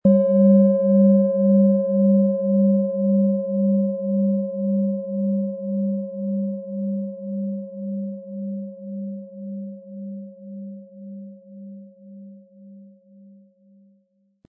OM Ton
Diese Planeten-Klangschale OM-Ton wurde in alter Tradition von Hand hergestellt.
Im Audio-Player - Jetzt reinhören hören Sie genau den Original-Klang der angebotenen Schale. Wir haben versucht den Ton so authentisch wie machbar aufzunehmen, damit Sie gut wahrnehmen können, wie die Klangschale klingen wird.
Aber dann würde der ungewöhnliche Ton und das einzigartige, bewegende Schwingen der traditionellen Herstellung fehlen.
MaterialBronze